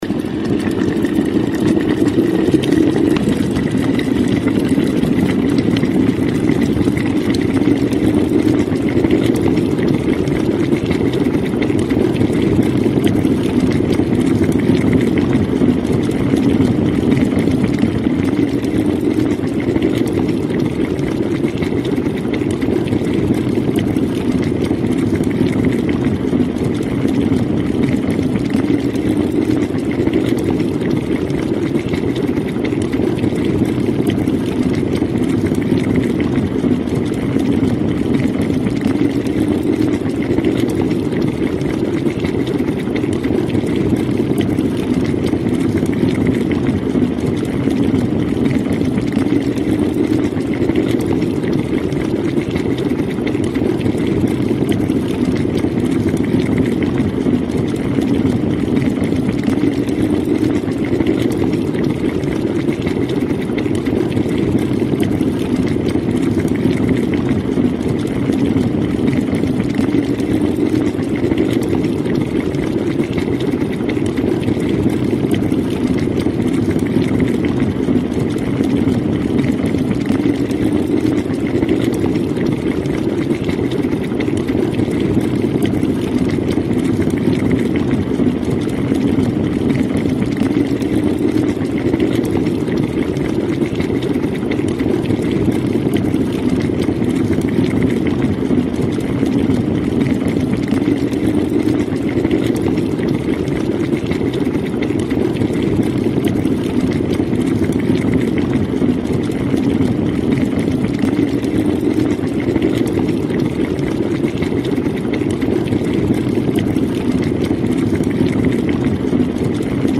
Вода закипает в чайнике